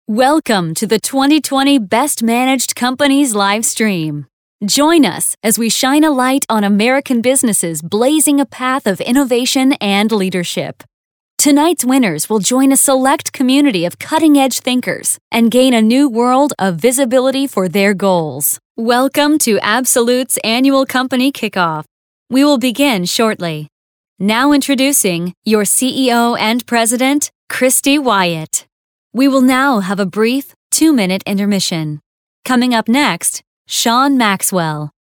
Live Announce - Female Voice of God (VOG)
Pre-recorded or live, in-person voiceover talent for your event.
Awards Ceremony